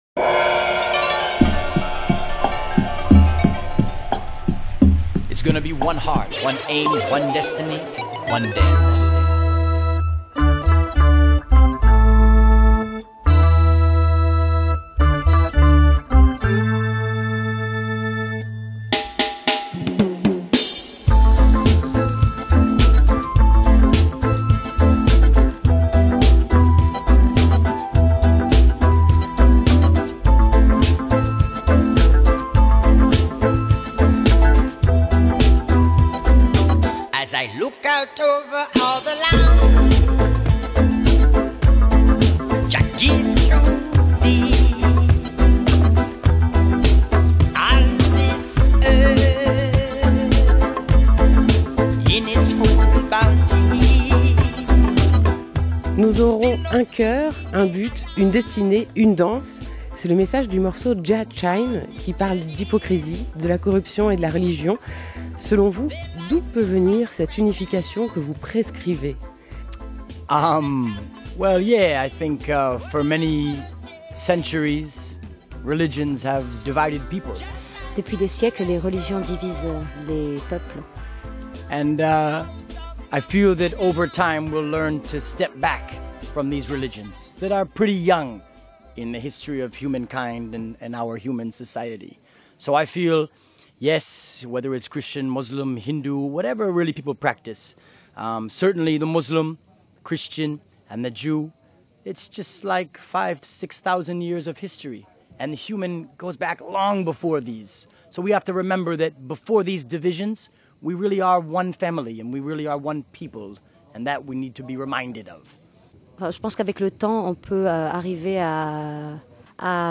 24 juillet 2016 20:00 | Interview
RadioLà-ITW-Harrison-Stafford-Groundation.mp3